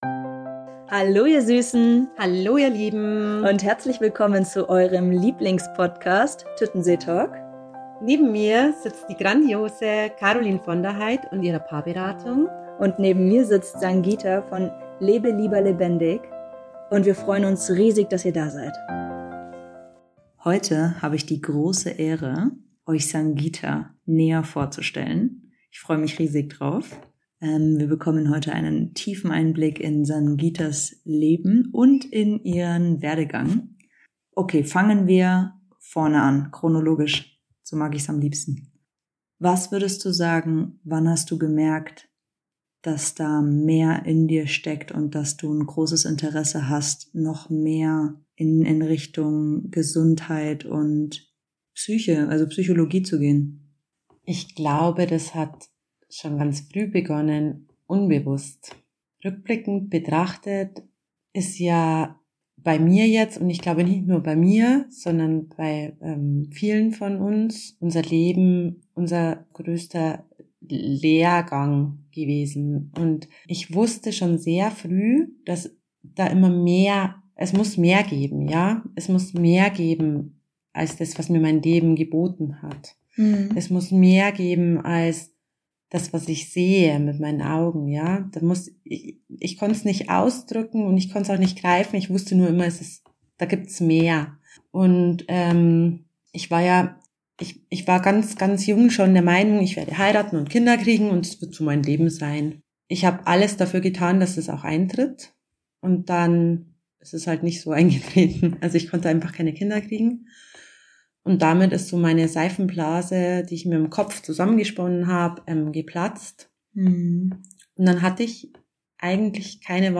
das Interview zu ihrem Werdegang